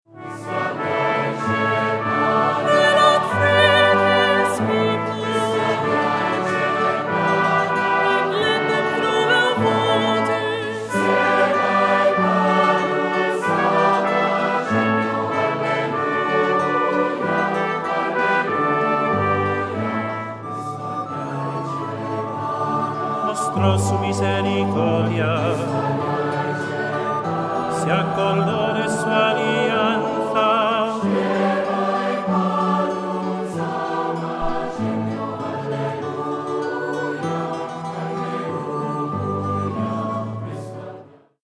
Voicing: SATB; Cantor; Solo; Assembly